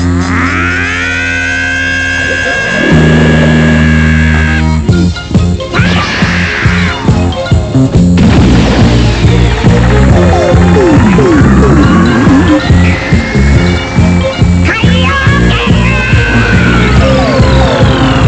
SCREAM
Este es un grito de Goku en la primera serie (como te puedes dra cuenta).
aaaaaahh.wav